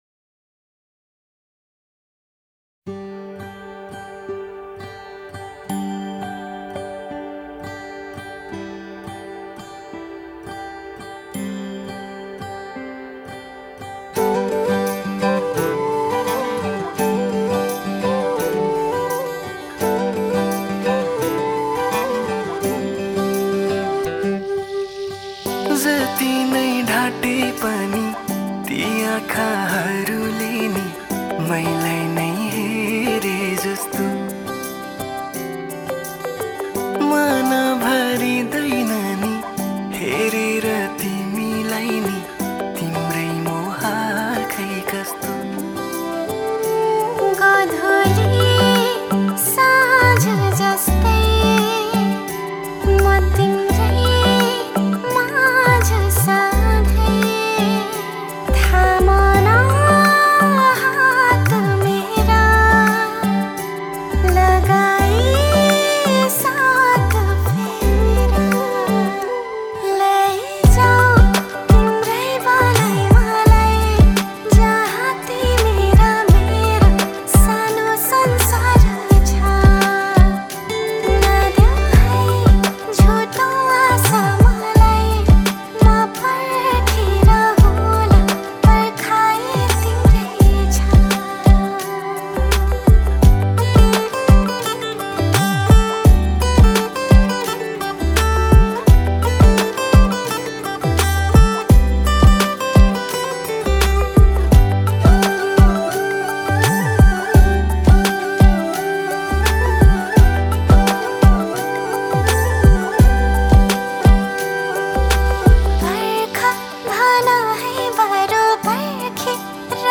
Nepali Romantic Song